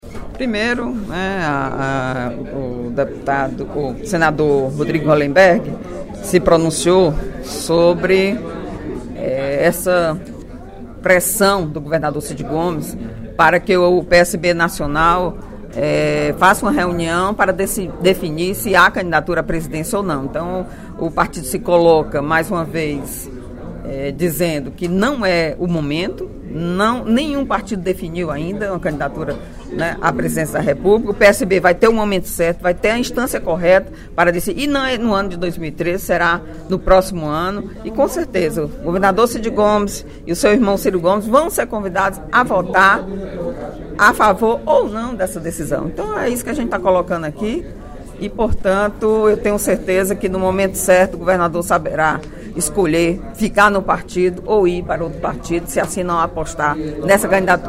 Em pronunciamento durante a manhã desta sexta-feira (26/04), a deputada Eliane Novais (PSB) criticou a decisão do presidente do Diretório Estadual do PSB e governador do Estado, Cid Gomes, de pedir à Direção Nacional da legenda que convoque uma reunião para discutir possível candidatura própria do PSB à Presidência da República.